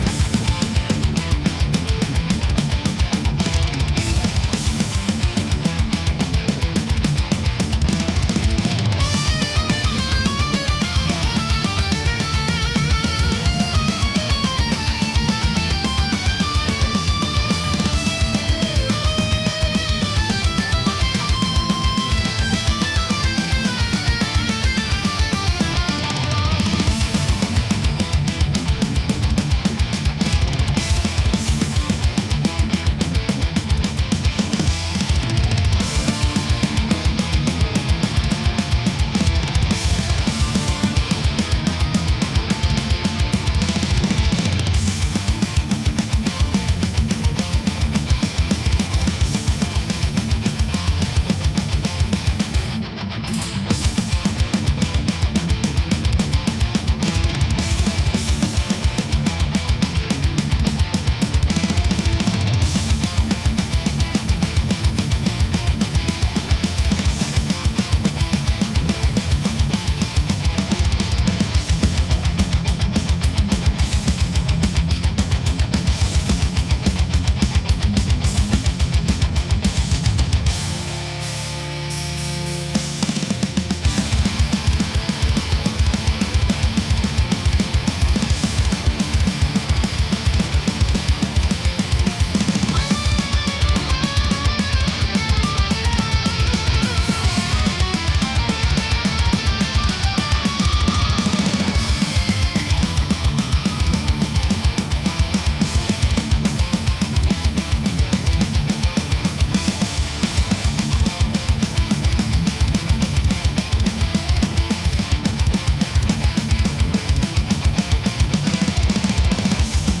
metal | aggressive